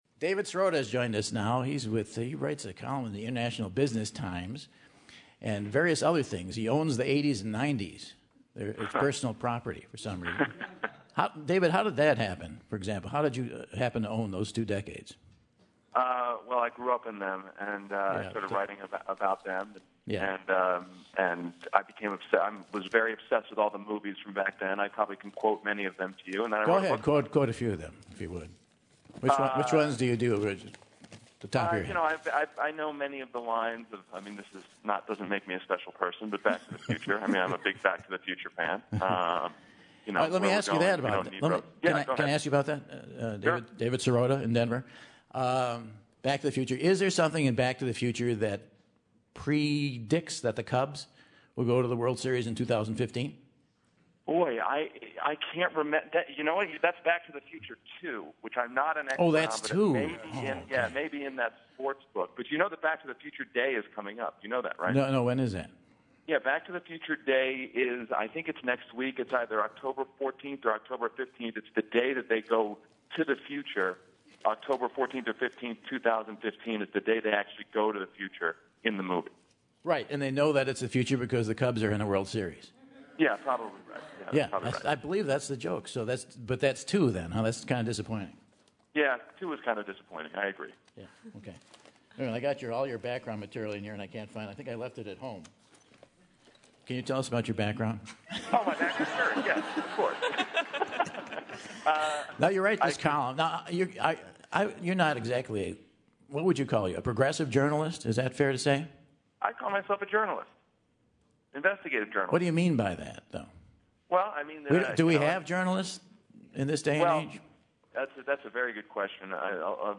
This week he takes to the phone to discuss the difference between media and journalists, and the current political landscape with Michael!